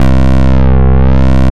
Index of /90_sSampleCDs/Trance_Explosion_Vol1/Instrument Multi-samples/Wasp Bass 1
C2_WaspBass_1.wav